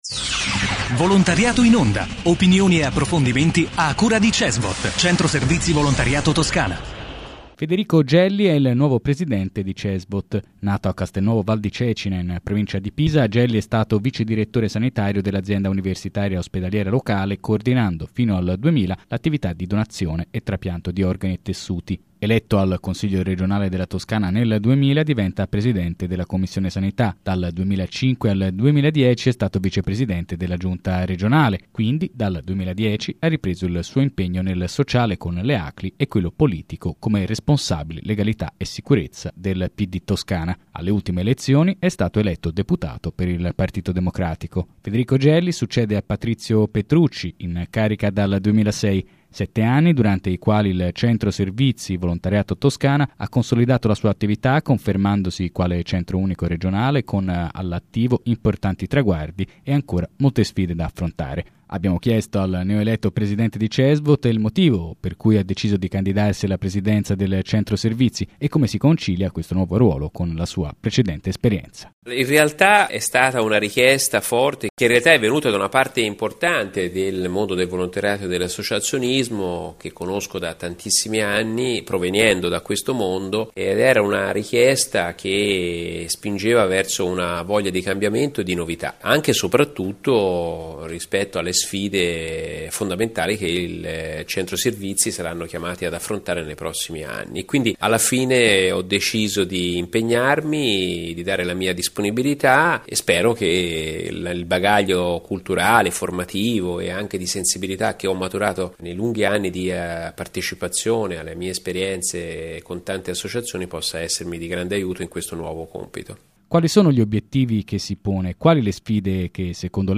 Intervista a Federico Gelli, nuovo presidente di Cesvot